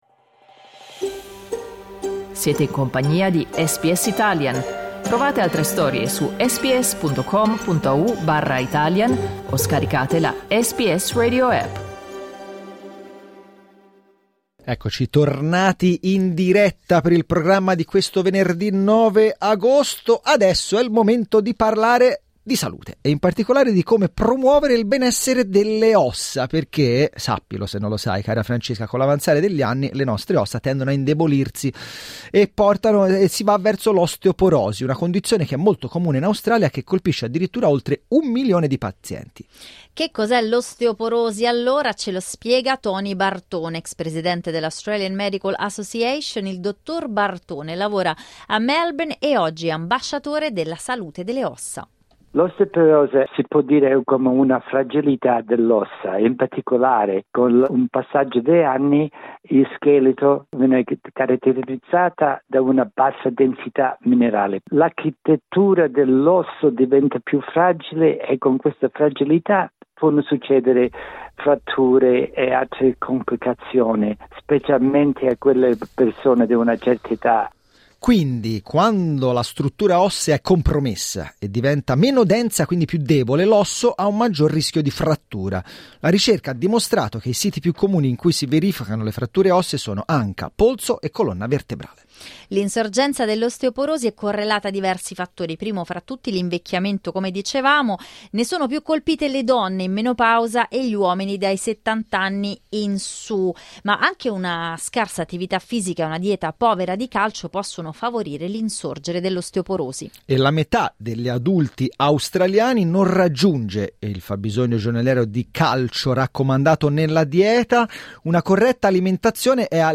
spiega ai microfoni di radio SBS
Clicca sul tasto "play" in alto per ascoltare il servizio In Australia, l'osteoporosi emerge come una condizione preoccupante, che colpisce oltre un milione di persone, rendendola una delle malattie più comuni nel continente.